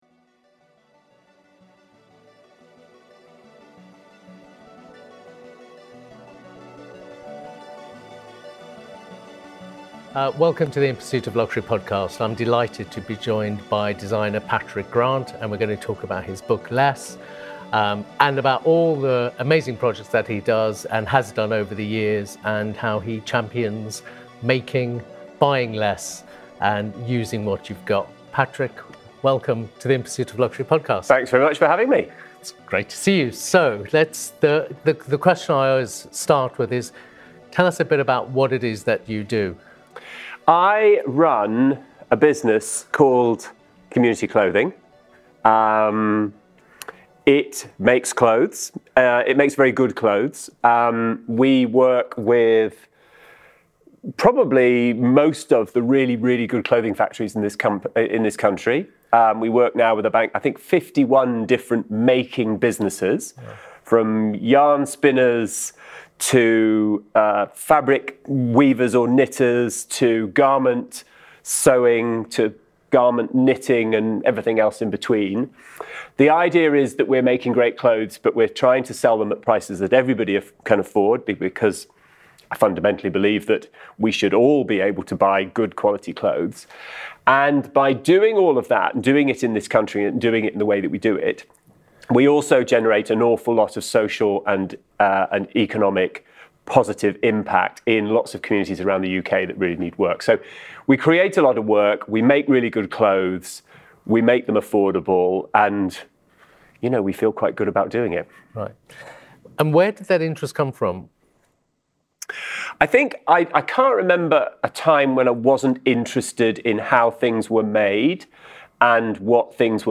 In conversation with Patrick Grant